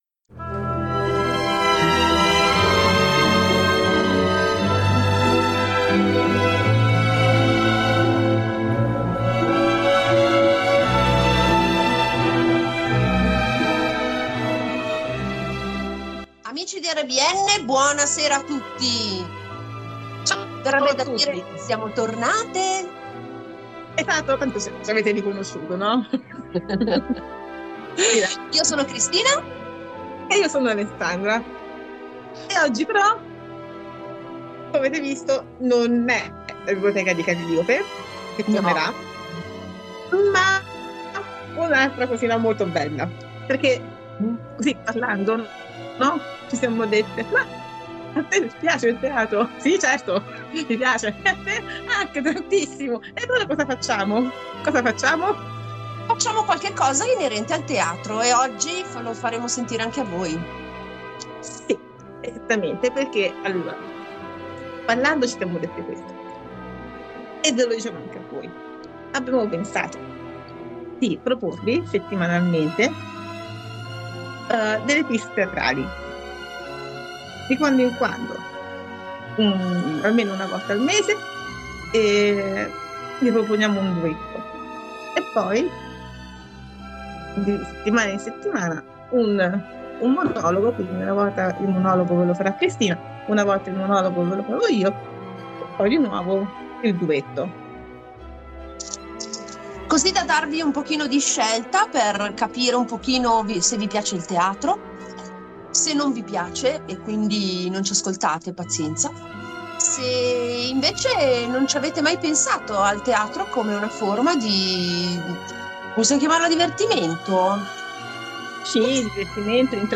In trasmissione ascolterete un interessante capovolgimenti del dialogo tra Eva e la Serpe (serpente).